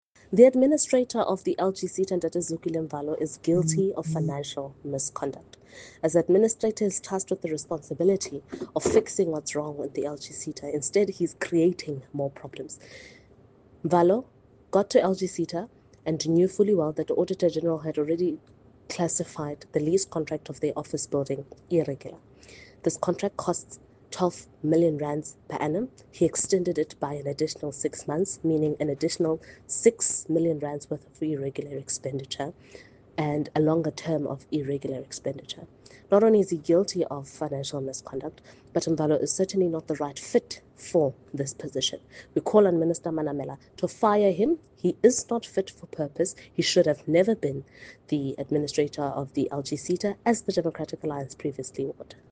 isiZulu soundbites by Karabo Khakhau MP.